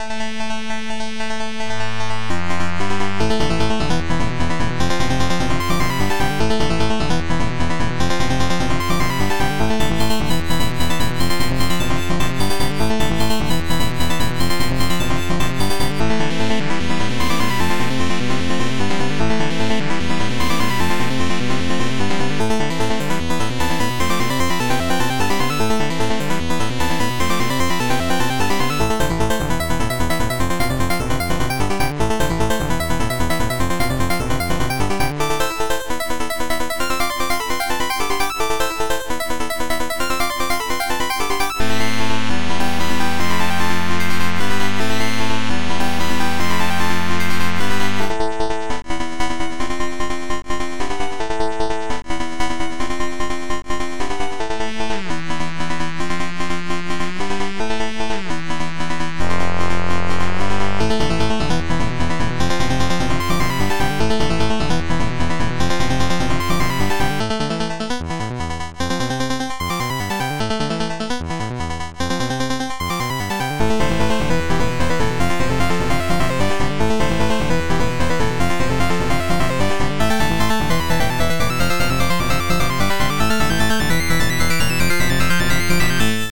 Extended Module
metal2.mp3